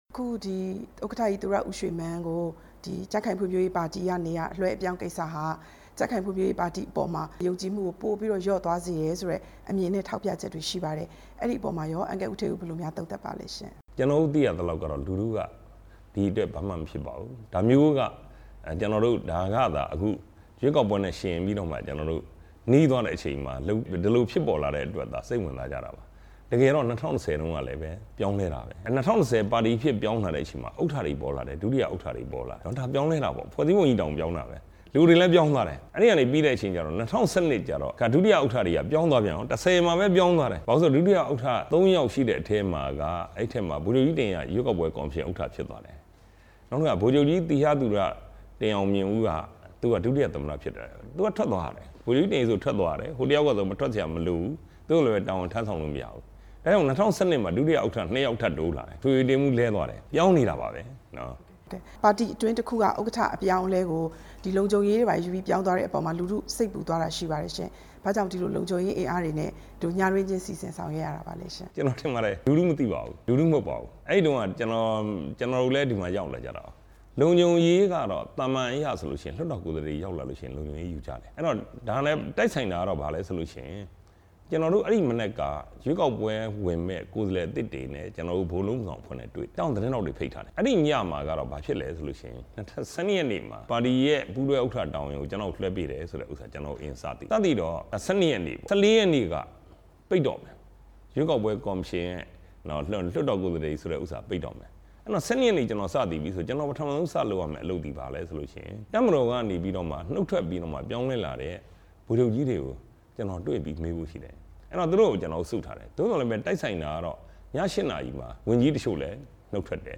ပြည်ခိုင်ဖြိုးပါတီ ပူးတွဲဥက္ကဌ ဦးဌေးဦးနဲ့ တွေ့ဆုံမေးမြန်းချက် (ဒုတိယပိုင်း)